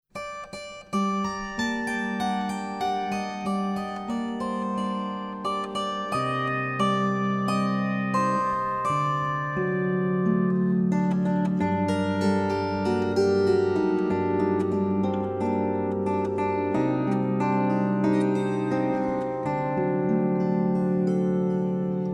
18 mélodies hébraïques à la cithare.
Musiques traditionnelles, adaptées pour cithare